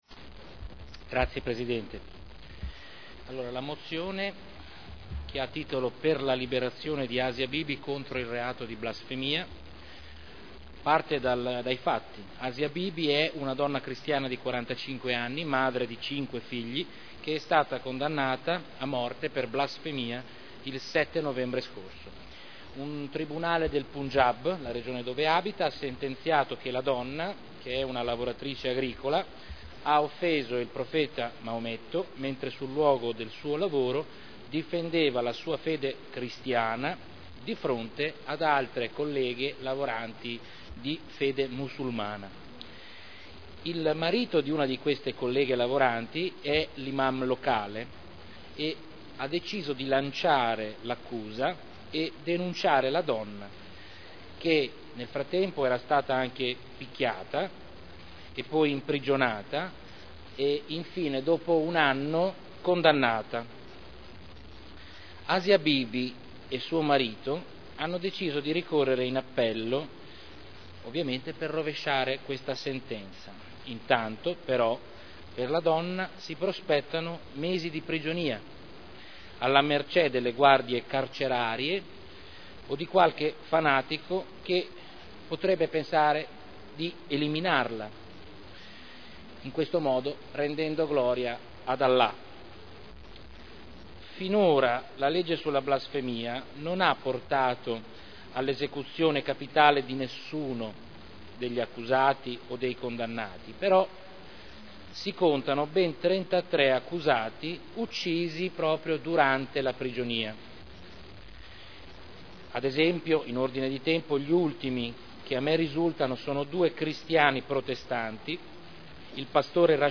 Seduta del 3/02/2011. Mozione presentata dai consiglieri Cotrino, Trande, Prampolini, Rossi F., Artioli, Glorioso, Sala, Rimini, Urbelli, Gorrieri, Campioli, Pini, Cornia, Morini, Guerzoni, Rocco e Goldoni (P.D.) per la liberazione di Asia Bibi, contro il reato di blasfemia